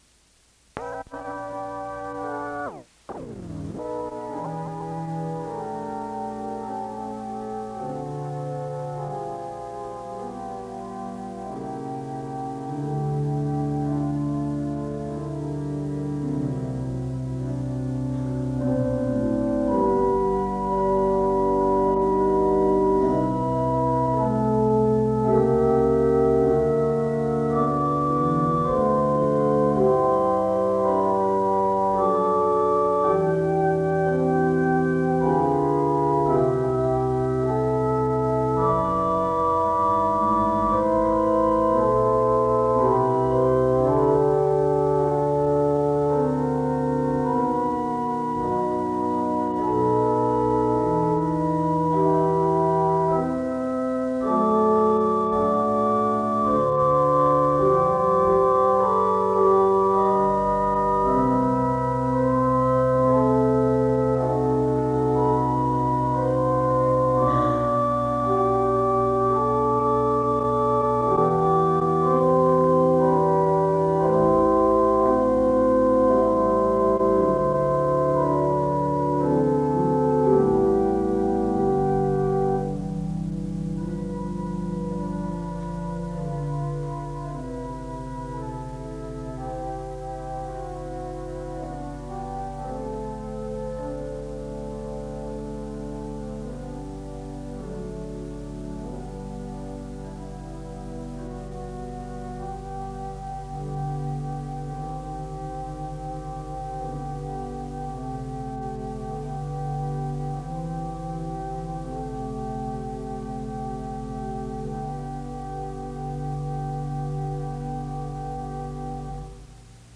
Instrumental music plays (0:00-01:59), and an opening prayer is offered to God (02:04-04:36).